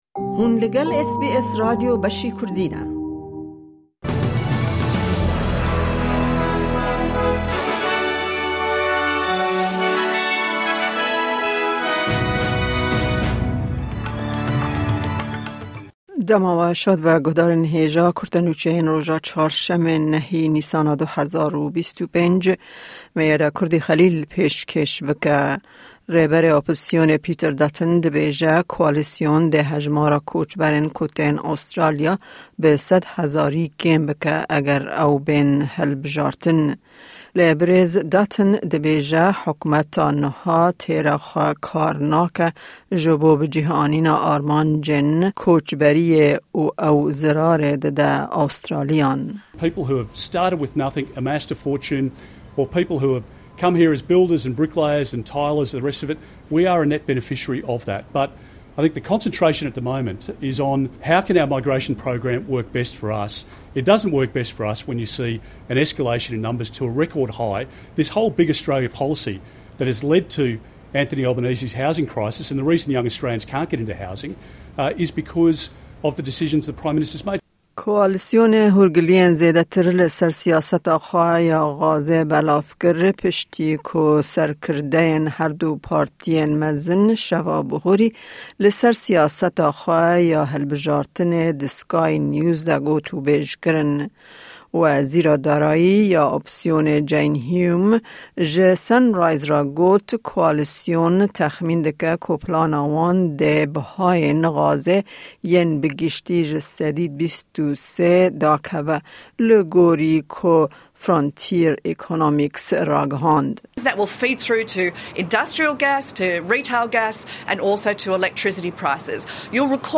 Kurte Nûçeyên roja Çarşemê, 9î Nîsana 2025